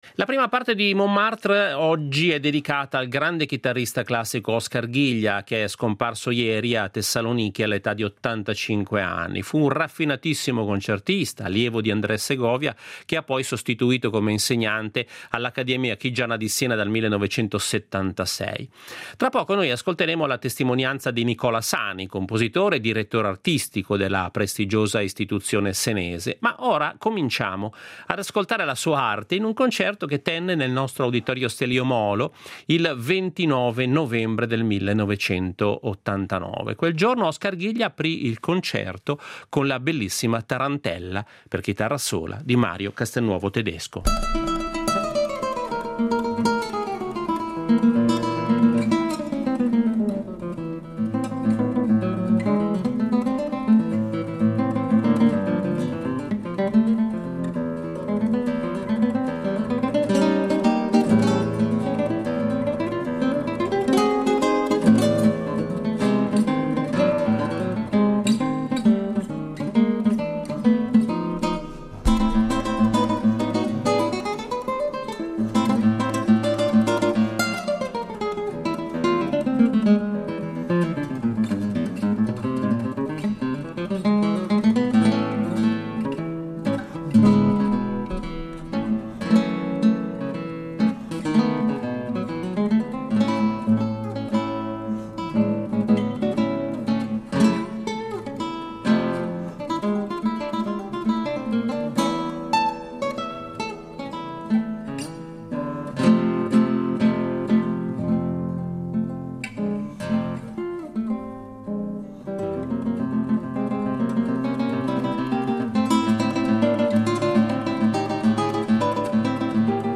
Nel 1989, tenne un concerto proprio qui, nel nostro Auditorio Stelio Molo di Lugano, dove eseguì pagine di Castelnuovo-Tedesco, De Falla, Turina, Rodrigo e Roussel.